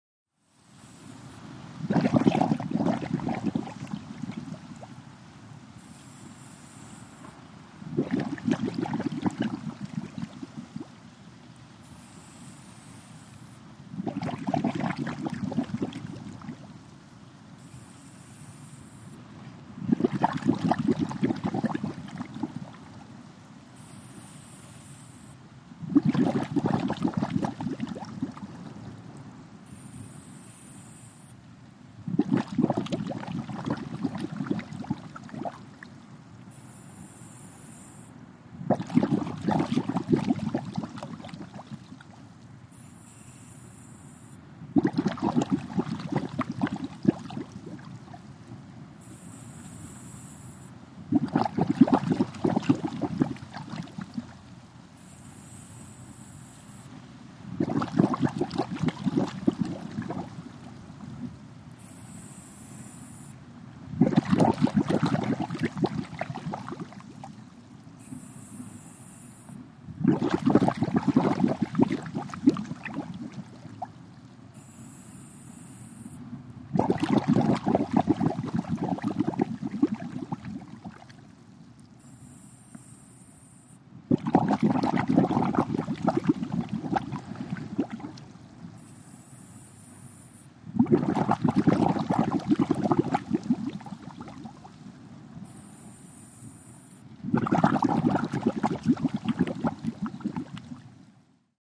WATER-UNDERWATER FX SCUBA: Regulator breathing & bubbles on surface, close up.